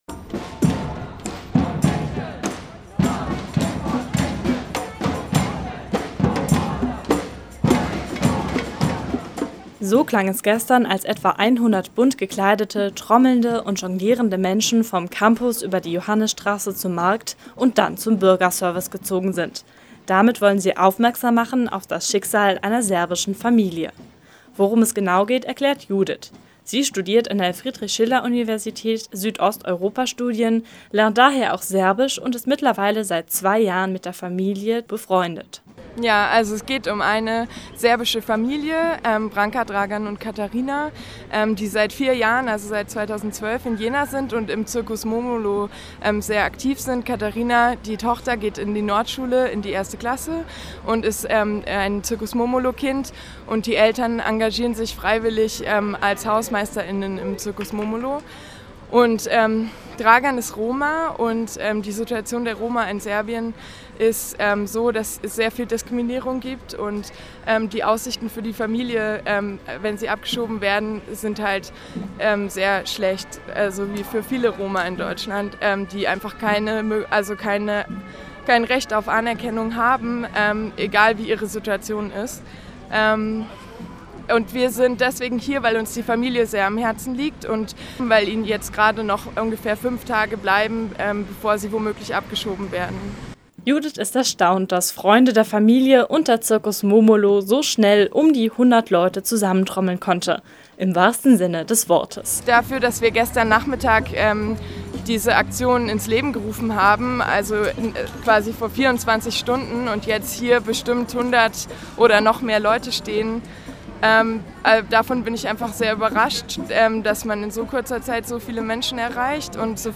Gestern sind etwa 100 Menschen trommelnd und bunt vom Campus zum Bügerservice gelaufen